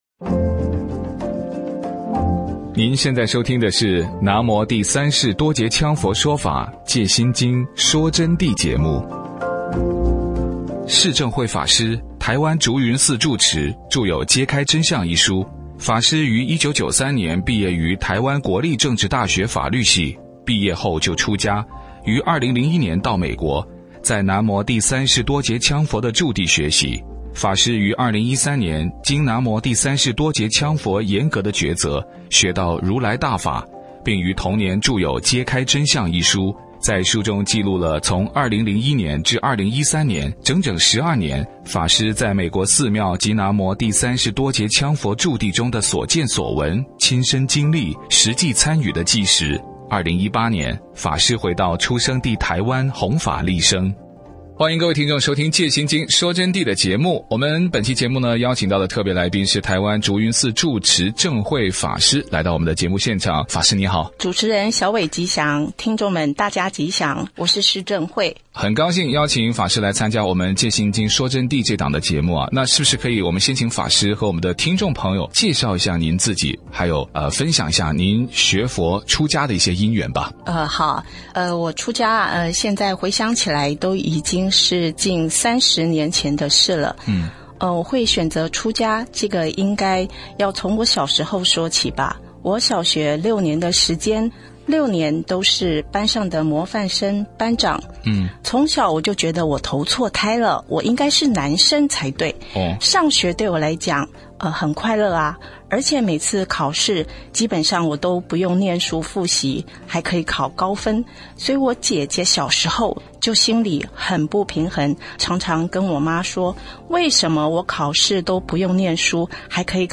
佛弟子访谈（四十）